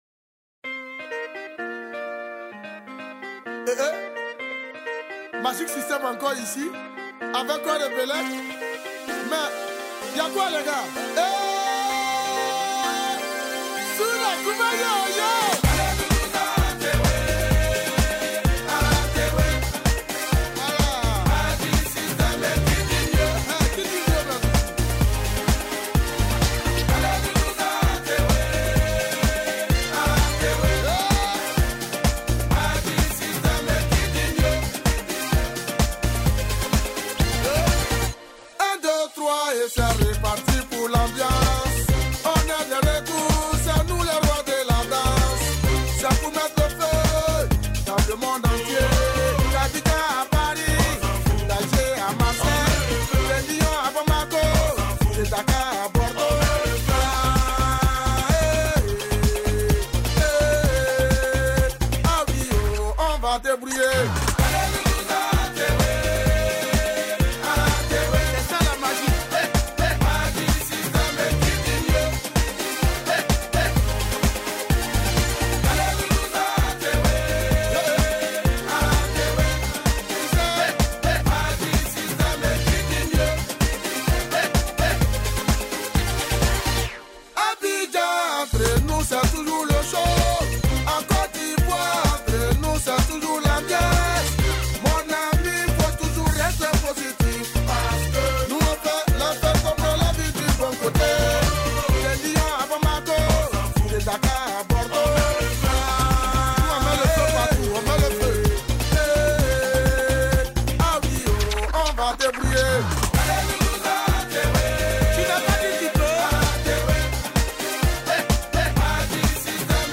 Эфиопская музыка